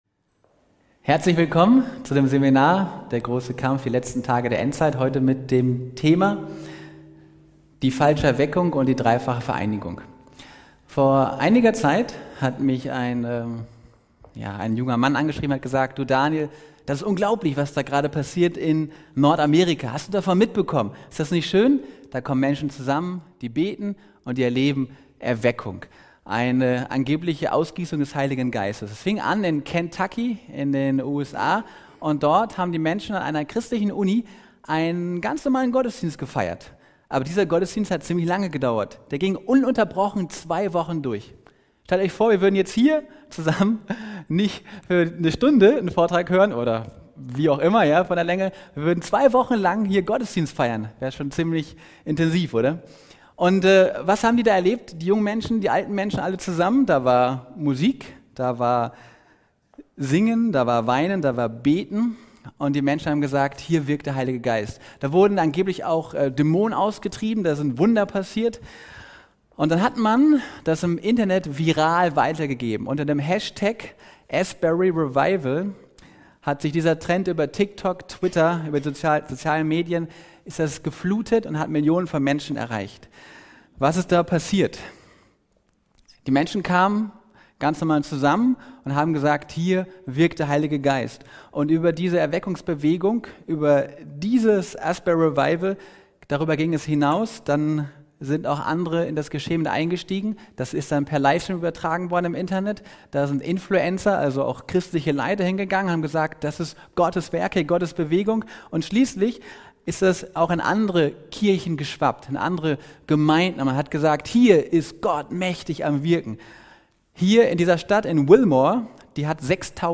In einem fesselnden Vortrag wird die Beziehung zwischen aktuellen Erweckungsbewegungen und prophetischen Zeiten beleuchtet. Es wird die Frage aufgeworfen, ob die jüngsten Erweckungen, wie das Asbury Revival, tatsächlich von Gott stammen oder ob sie satanisch beeinflusst sind. Zudem wird untersucht, wie Satan durch falsche Lehren und spiritistische Phänomene die Welt täuschen könnte.